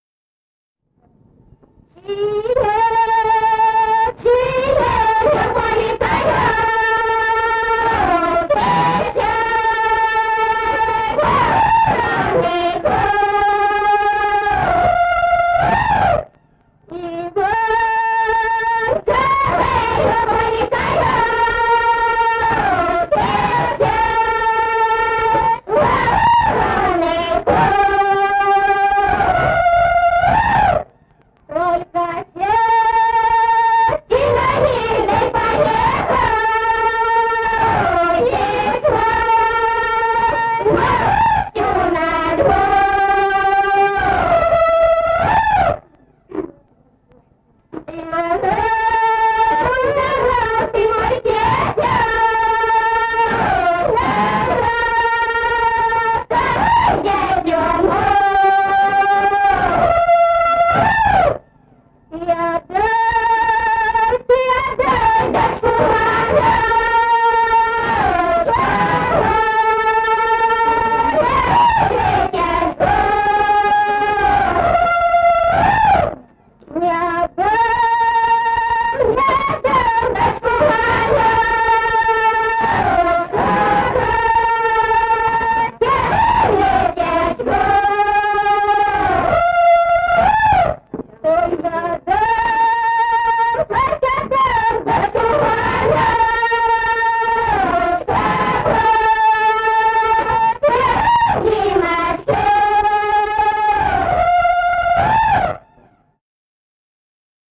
Народные песни Стародубского района «Чие, чие во поле колёски», жнивная.
1954 г., с. Курковичи.